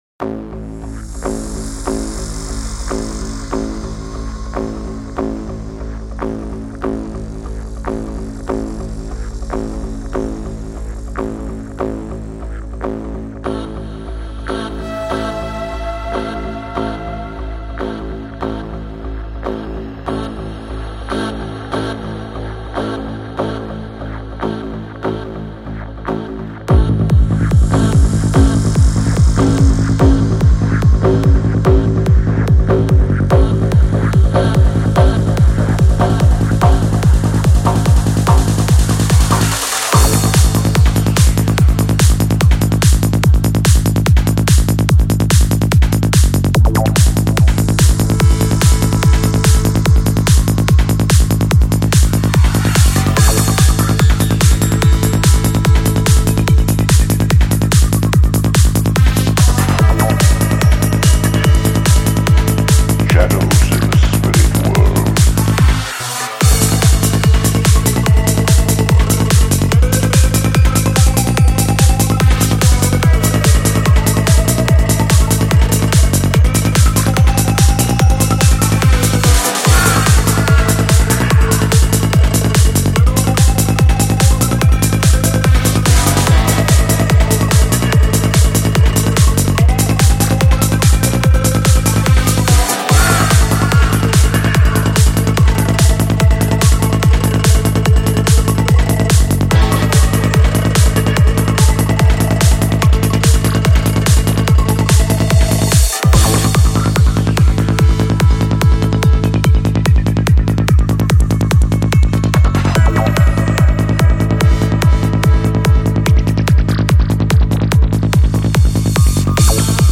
ژانر : هارد سایکو تمپو : 145